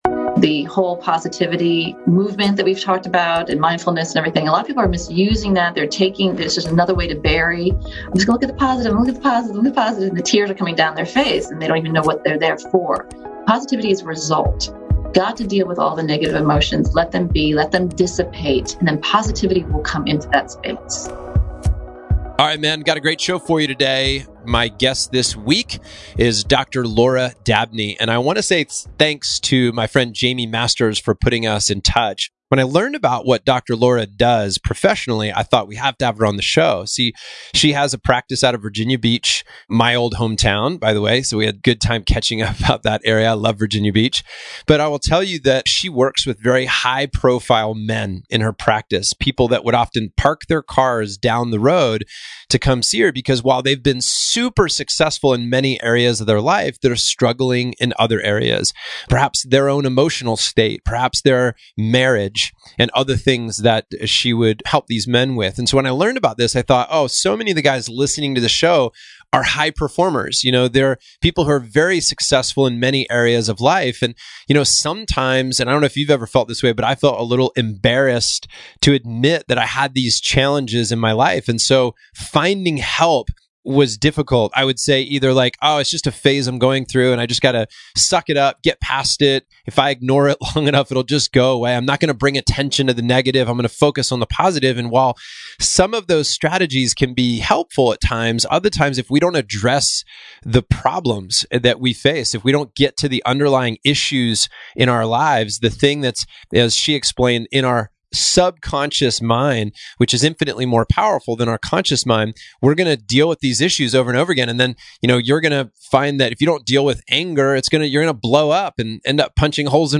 In this conversation, we’ll explore… Why venting doesn’t have to be out loud When and … How Men Deal With Neediness, Anger and Sadness Read More »